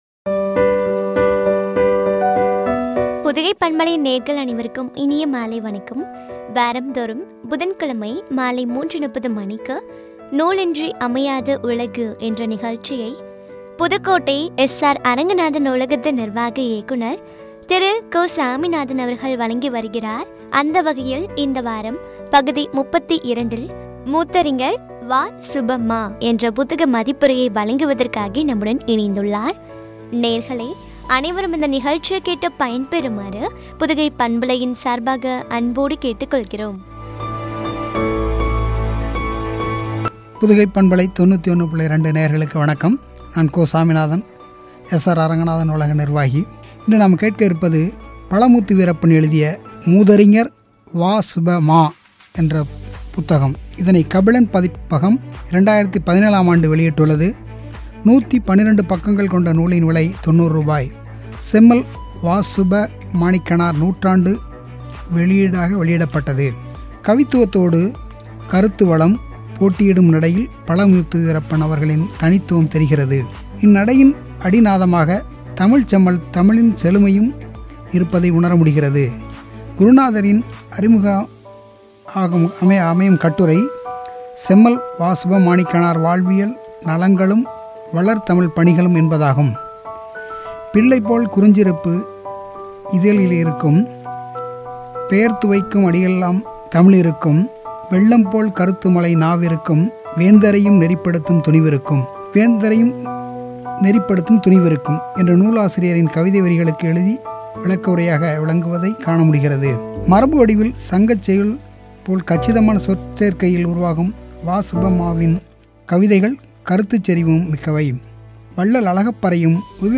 “மூதறிஞர் வ சு .மா” புத்தக மதிப்புரை (பகுதி -32) குறித்து வழங்கிய உரையாடல்.